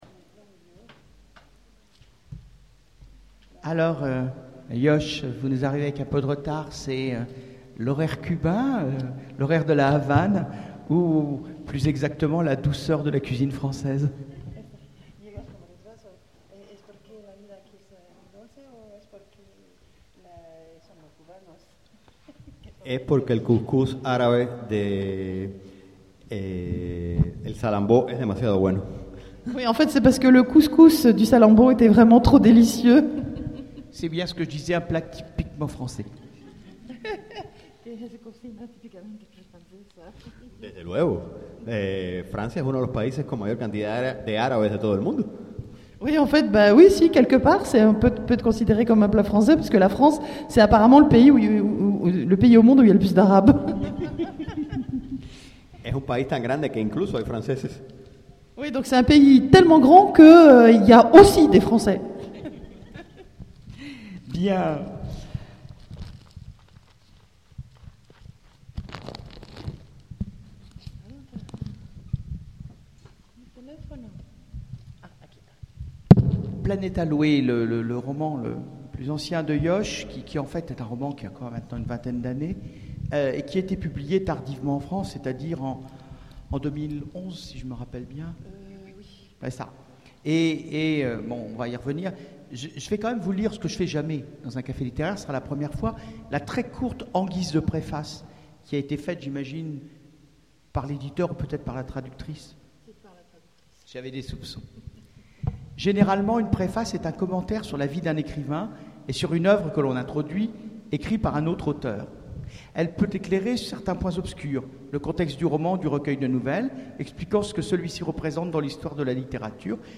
Imaginales 2014 : Entretien